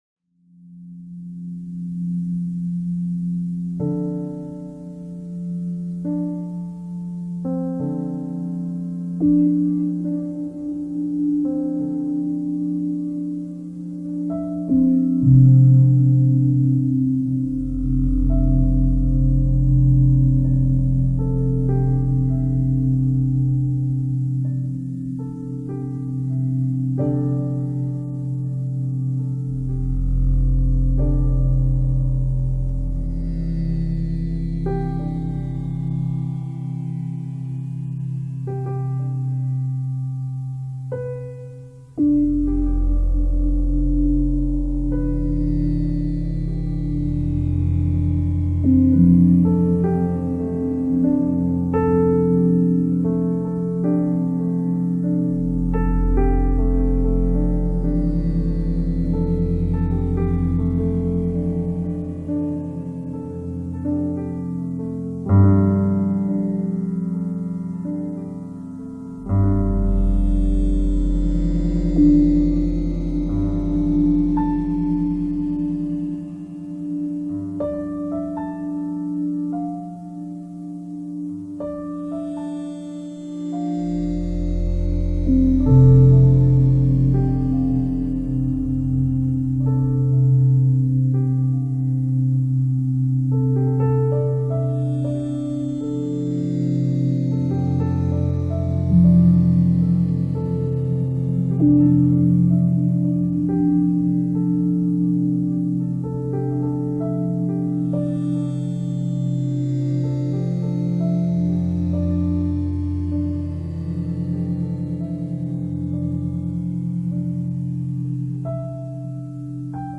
Musique relaxante méditation profonde 2
2025 MUSIQUE RELAXANTE, EFFETS SONORES DE LA NATURE audio closed https
Musique-relaxante-meditation-profonde-2-1.mp3